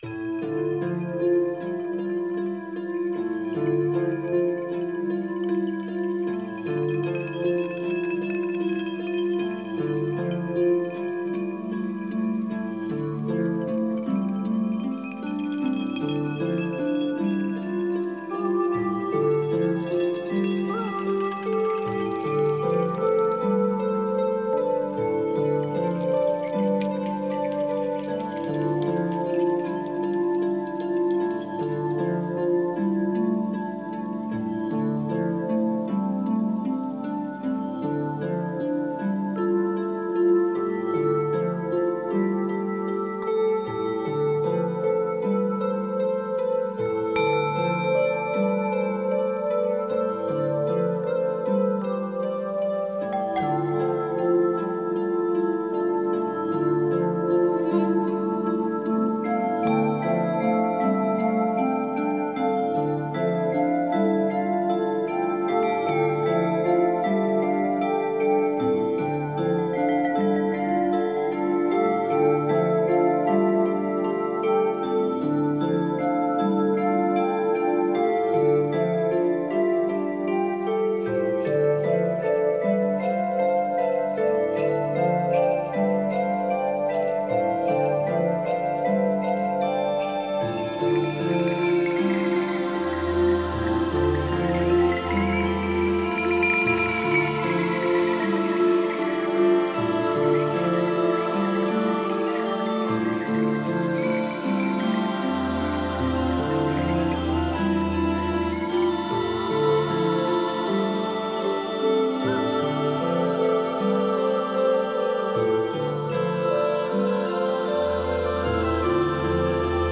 live in Carpi
tastiere, samples, chitarra, tin whistle
voce recitante
flauti e sax soprano
percussioni